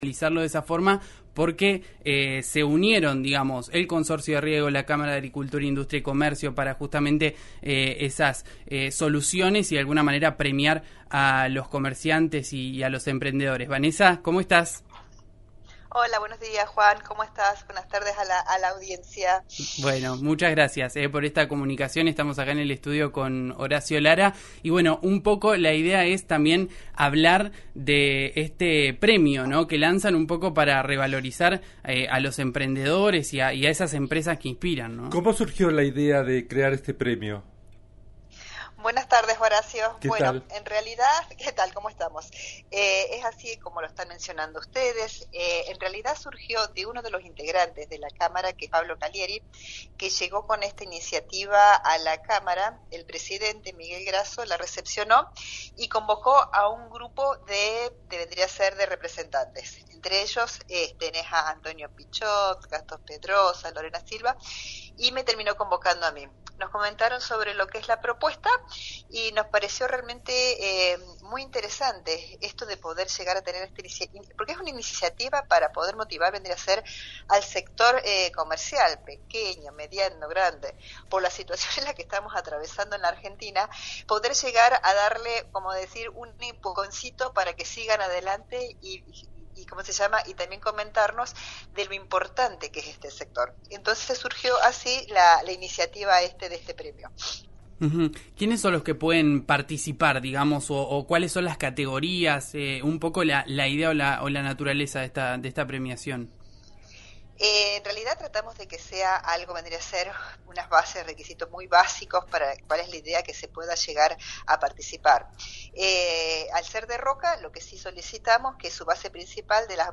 En dialogo con el programa DIGO que se emite de lunes a viernes de 14 a 15 por RÍO NEGRO RADIO